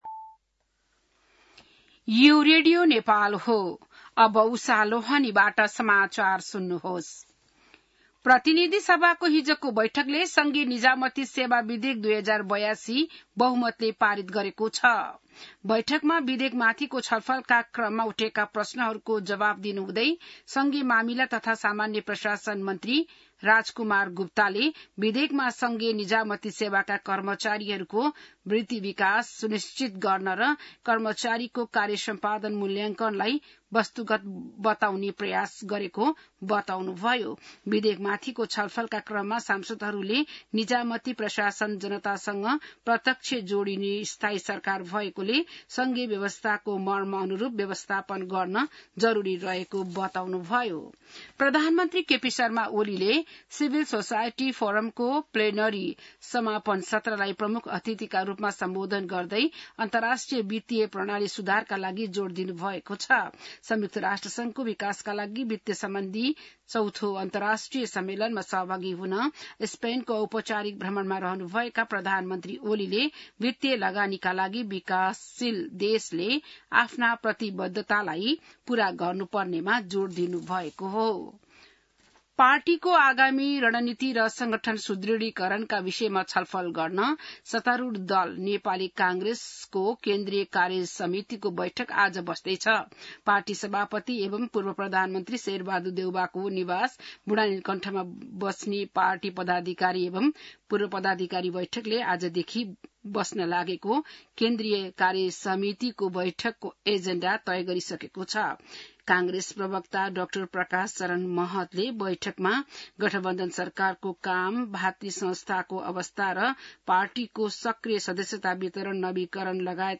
बिहान १० बजेको नेपाली समाचार : १६ असार , २०८२